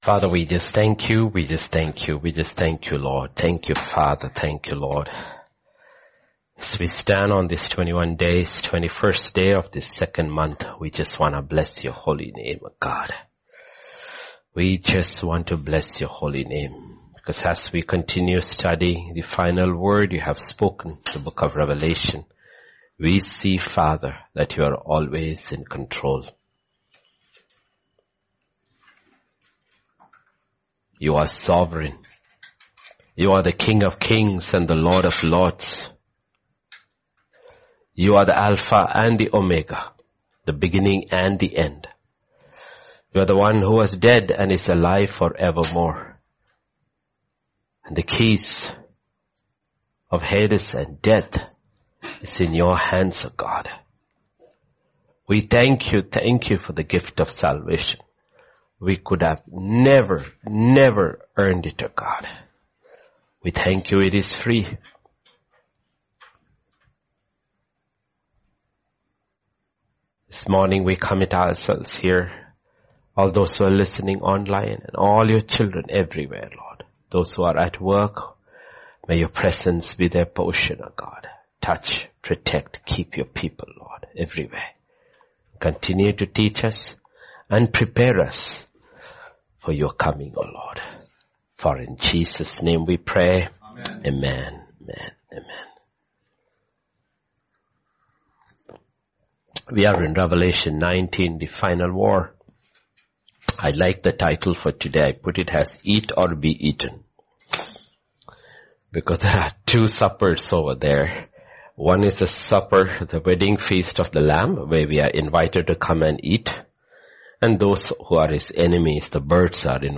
Sermons | Grace Tabernacle, Hyderabad Sermons |